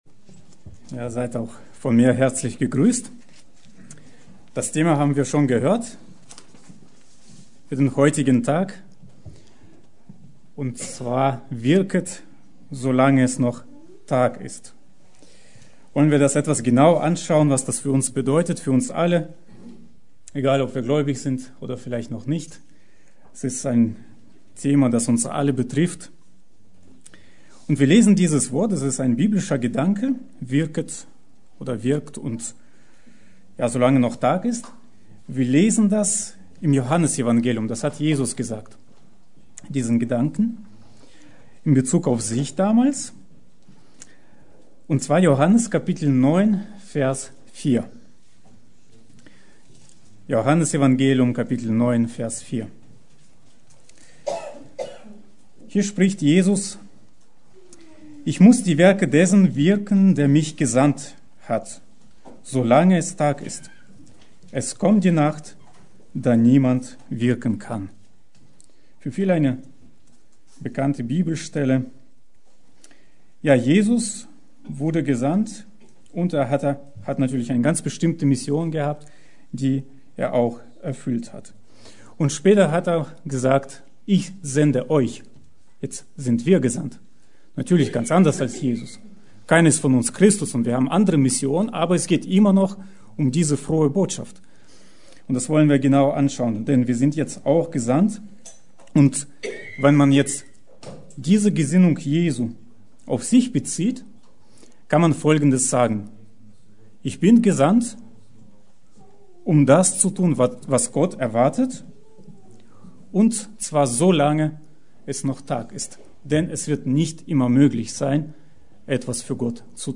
Predigt: Wirket solange es Tag ist!
Die Predigt wurde anlässlich des Missionsfestes im Missionshaus in Flehingen gehalten.